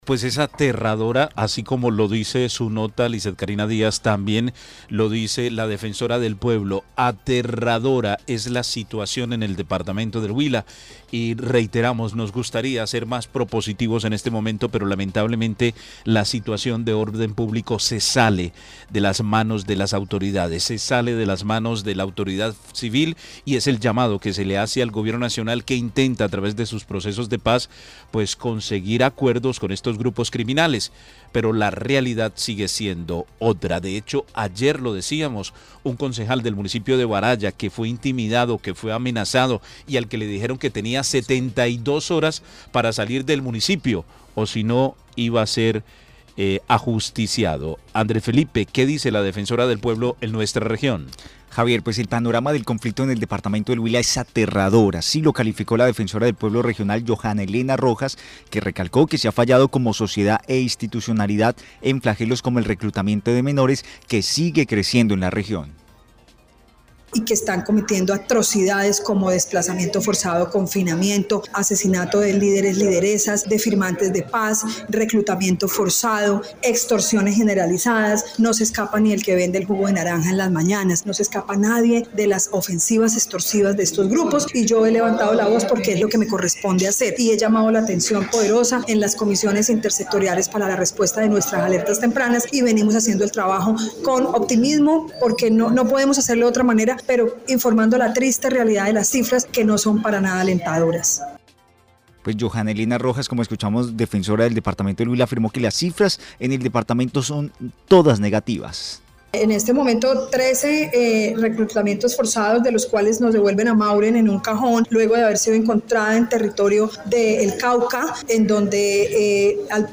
Johana Elena Rojas Defensora Regional Huila afirmó que las cifras en el departamento son negativas. Una docena de líderes asesinados en lo corrido del 2024 y más de 300 personas que han tramitado solicitudes por amenazas contra su integridad .